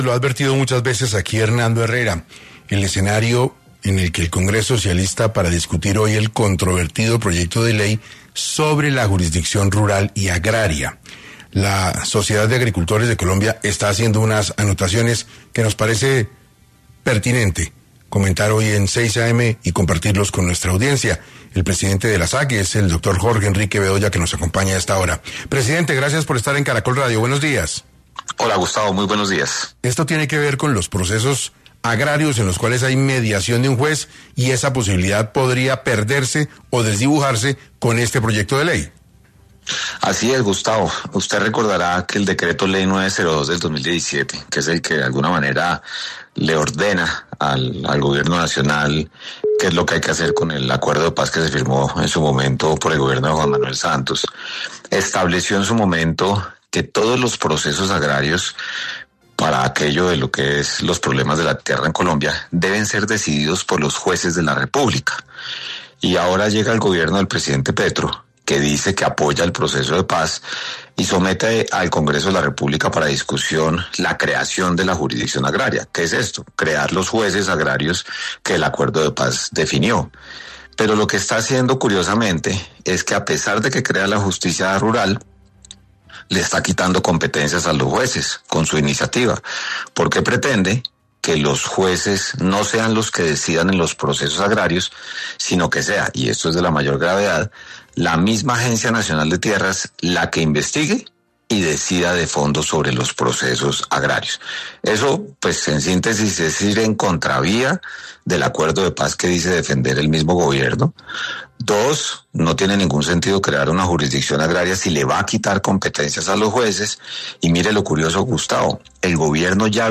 estuvo en 6AM para hablar de las discusiones actuales, alrededor de la justicia rural.